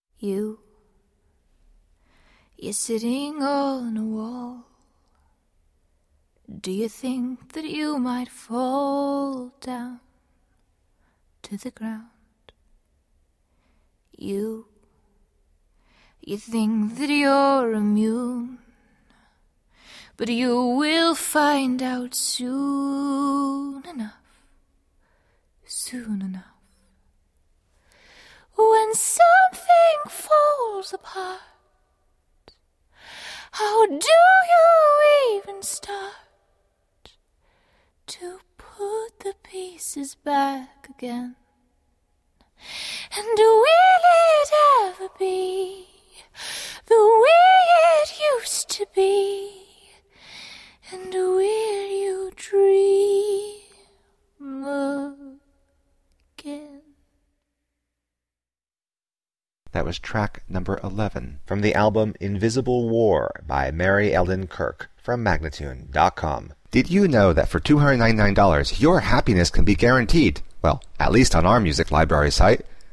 Pretty pop that sounds like jewel-tone colors.